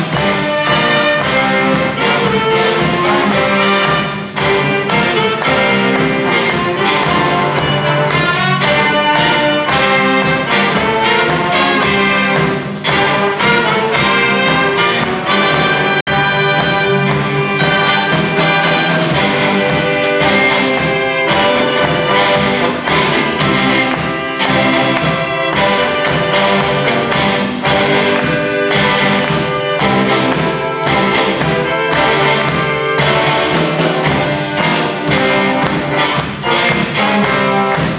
２００２年おとなのコンサート　〜　恋はパープル
今年はこの曲があったから、ハードロックの響きが必要と思いましてね。